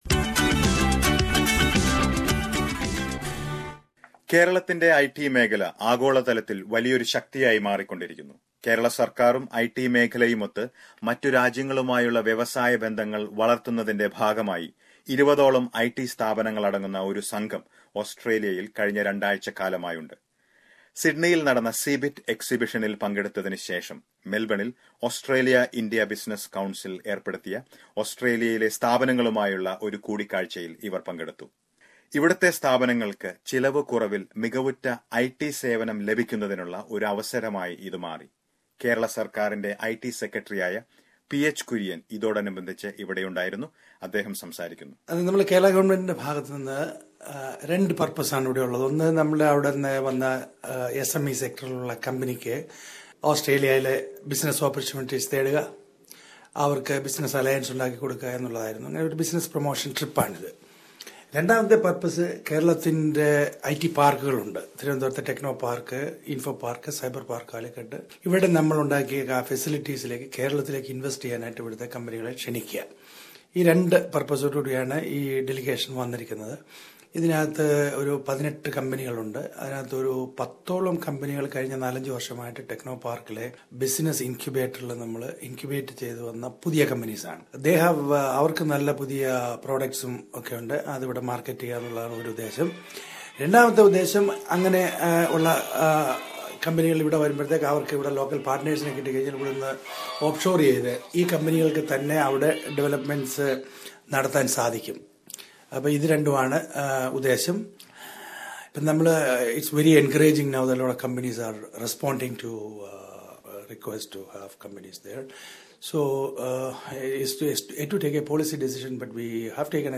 Small and medium scale ICT companies in Kerala are trying to tap into the Australian market. A delegation from Kerala had discussions with various Australian state governments. Listen to a report from Melbourne